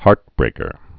(härtbrākər)